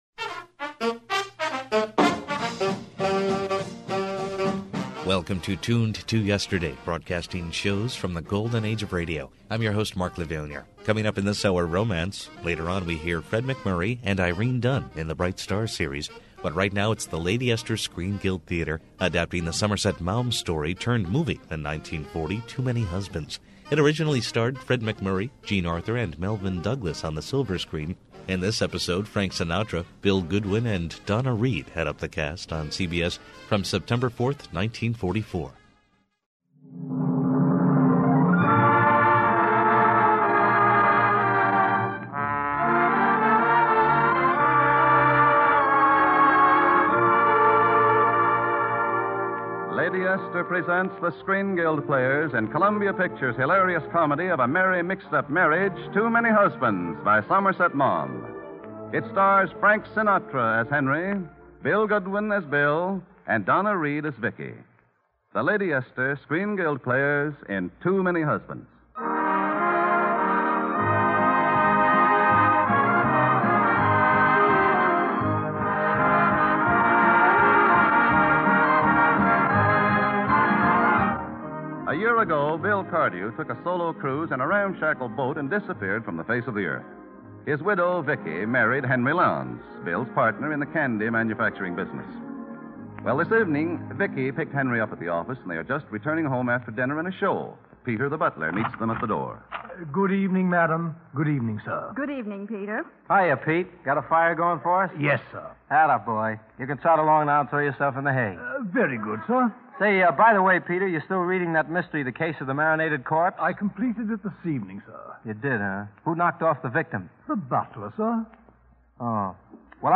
The highest quality broadcasts are restored and played as they were heard years and years ago.